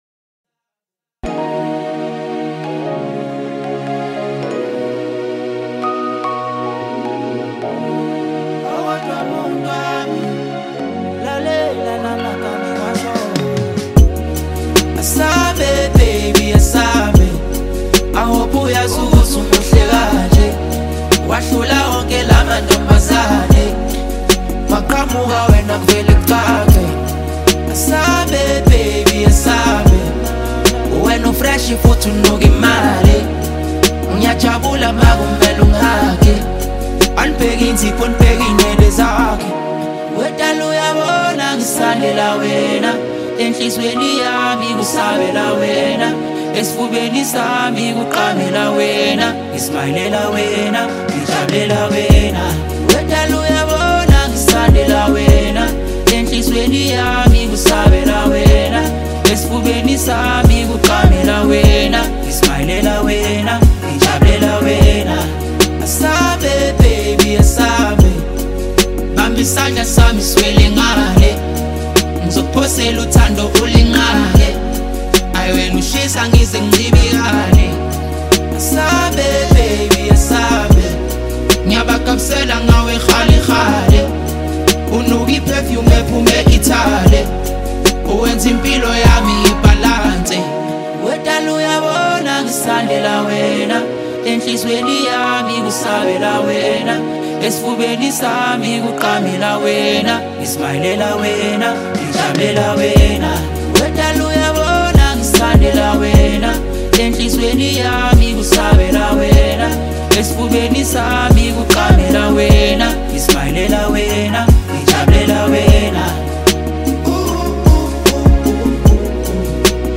vibrant sound, strong energy, smooth delivery